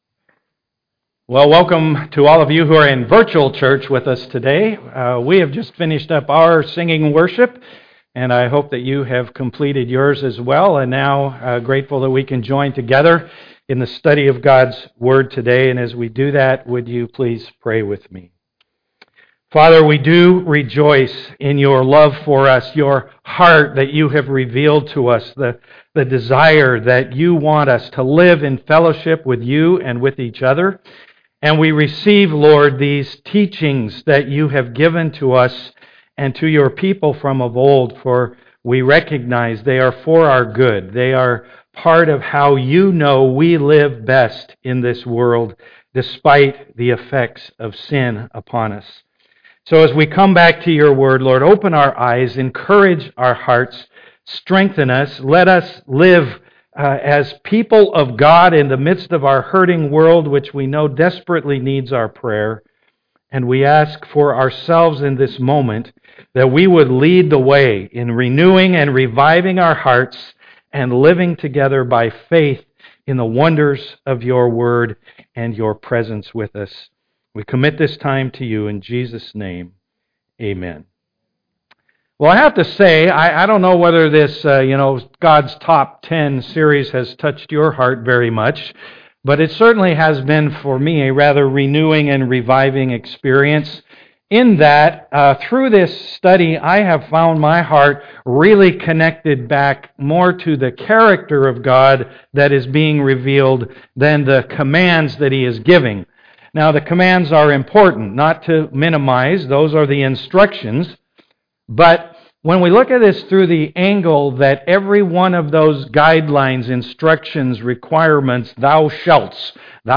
The message will be recorded live on Sunday morning and posted by early afternoon.